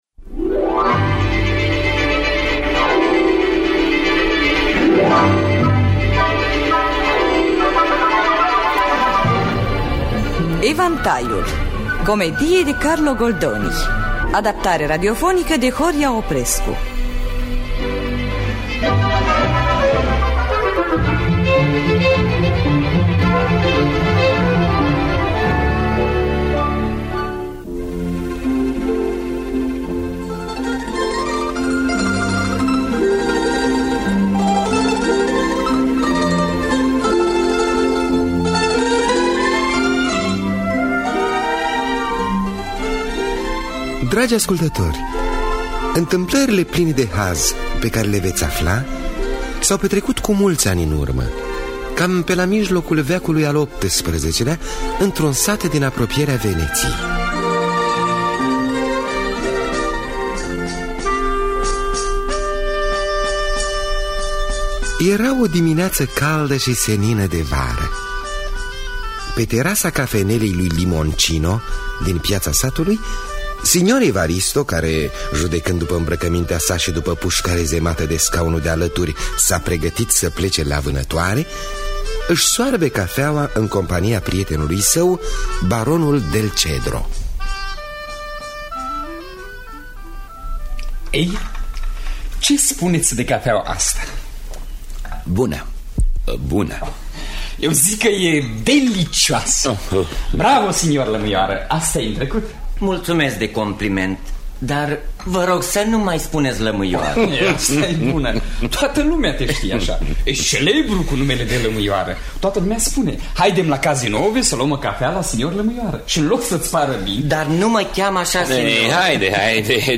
În distribuţie: Tanţi Cocea, Ion Lucian, Nicolae Brancomor, Nineta Gustti, Ştefan Mihăilescu Brăila.